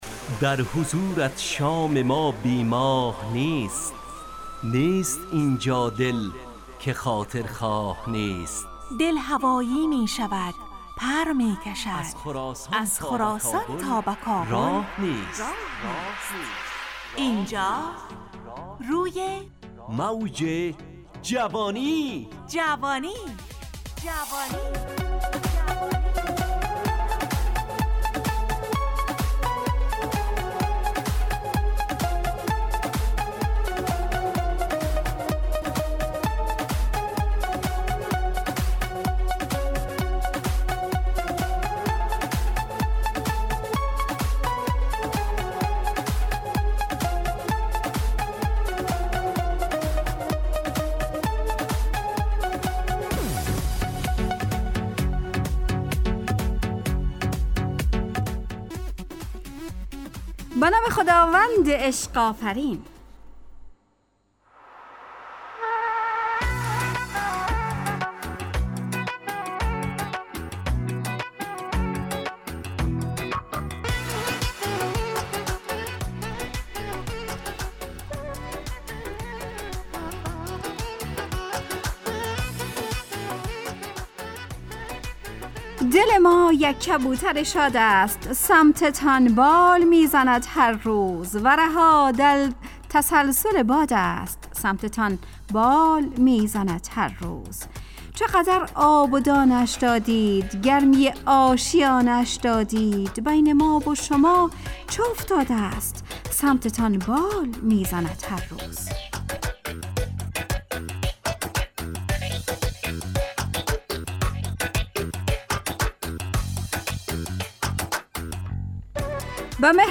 همراه با ترانه و موسیقی مدت برنامه 70 دقیقه . بحث محوری این هفته (دل)
روی موج جوانی برنامه ای عصرانه و شاد